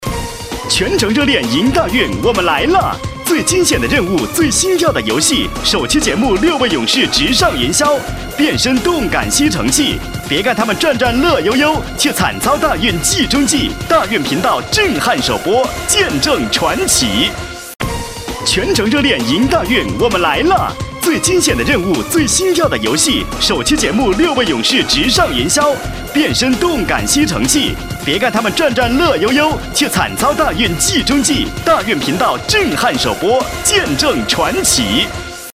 国语青年激情激昂 、沉稳 、积极向上 、男广告 、300元/条男S39 国语 男声 促销广告-阳光欢快促销大闸蟹 激情激昂|沉稳|积极向上